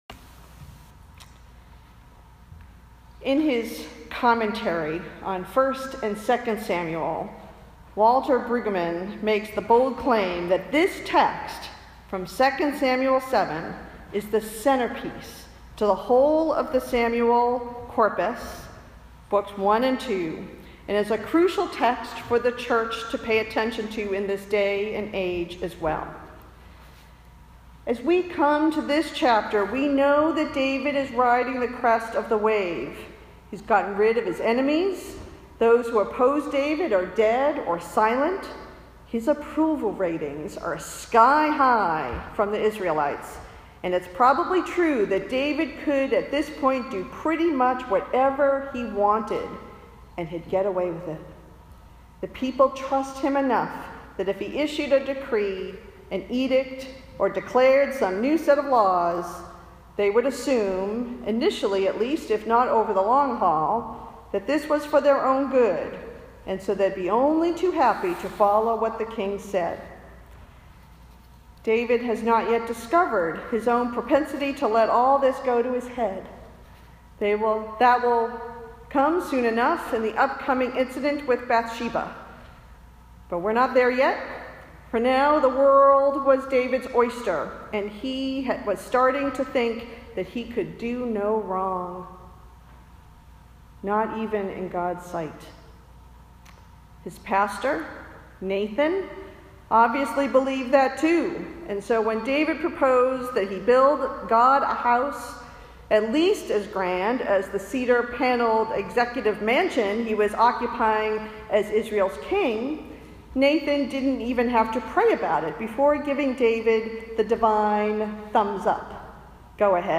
Related Posted in Sermons (not recent)